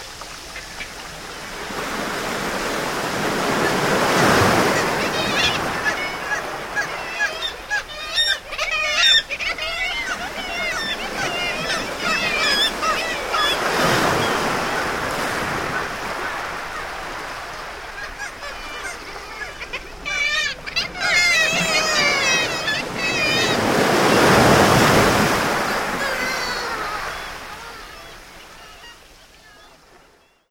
• waves and kittiwakes flying.wav
waves_and_kittiwakes_flying_a9h.wav